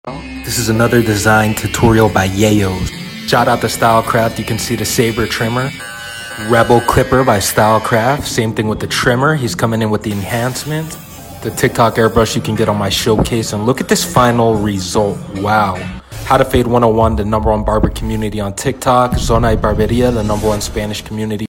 Barber POV + Voiceover